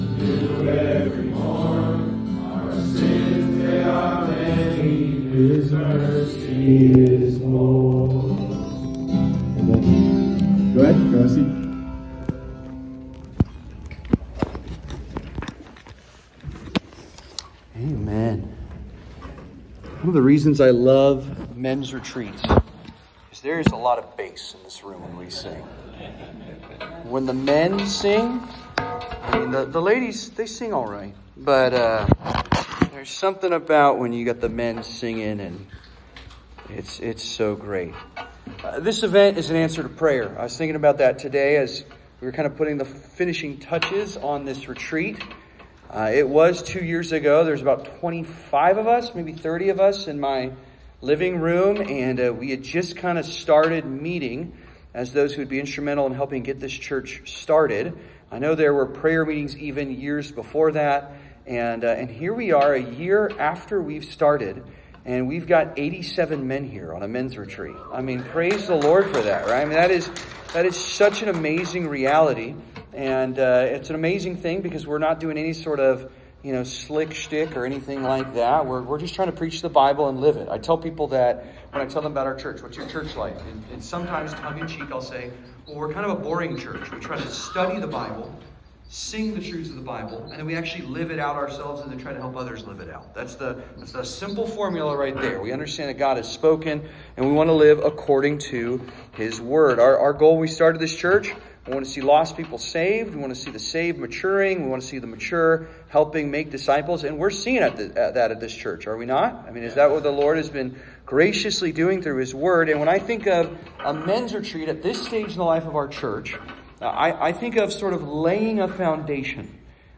Mens Retreat Spring 2026